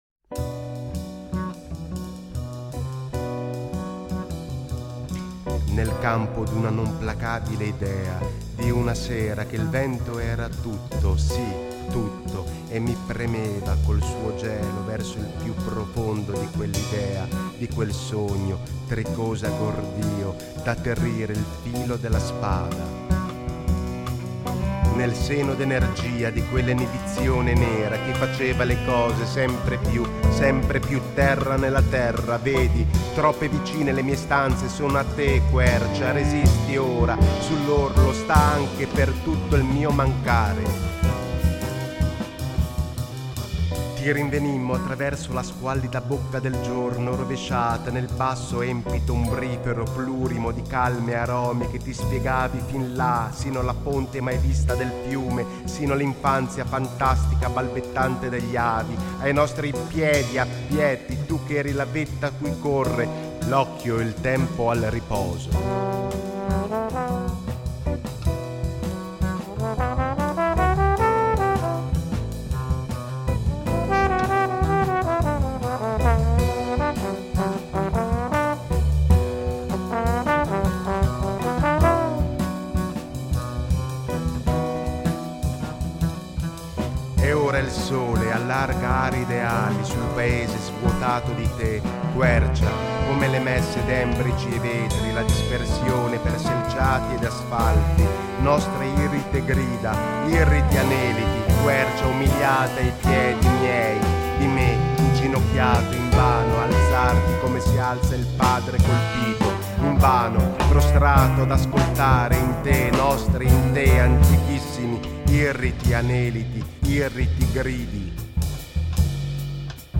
contrabbasso